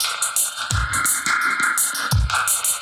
Index of /musicradar/dub-designer-samples/85bpm/Beats
DD_BeatFXB_85-01.wav